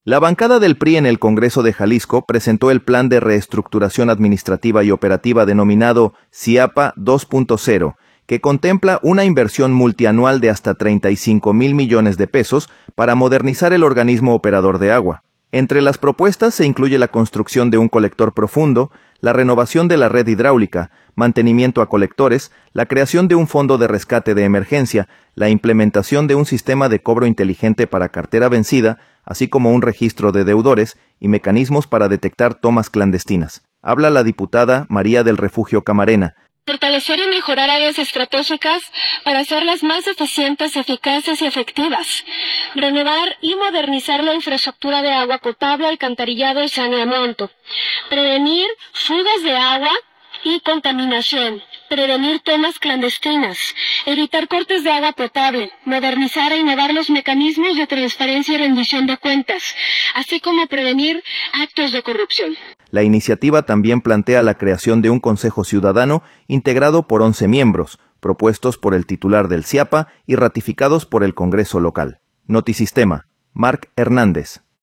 Habla la diputada María del Refugio Camarena.